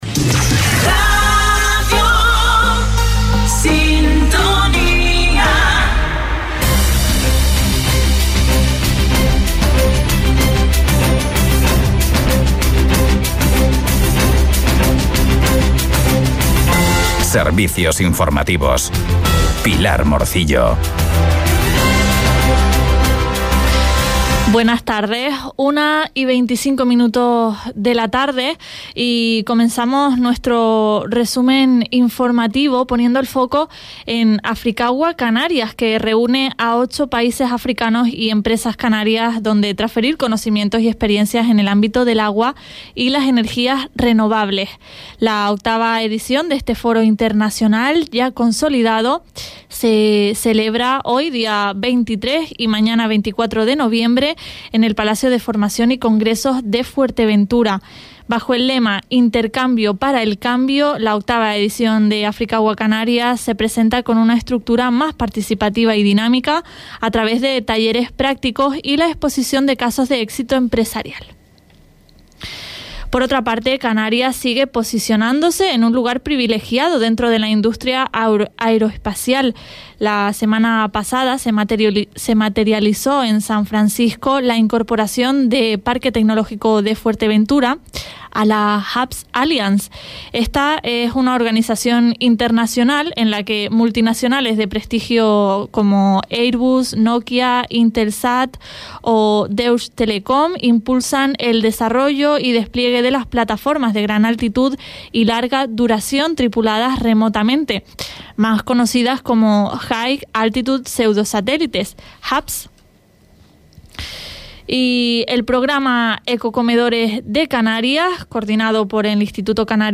Informativos en Radio Sintonía – 23.11.23